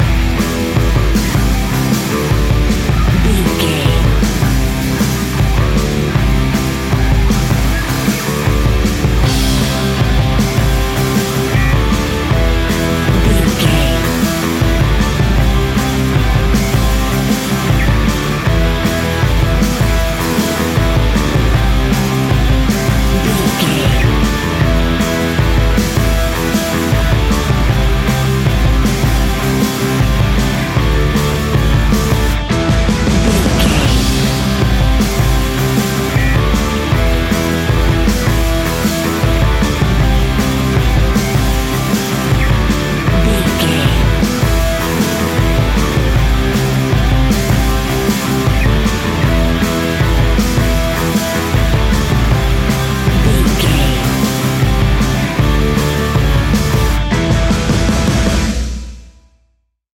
Ionian/Major
A♭
hard rock
distortion
instrumentals